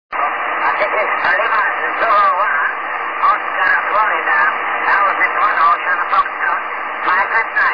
Signaux entendus sur RS13, bande 2m, le 05/05/2002 vers 2045 UTC :
une station en SSB.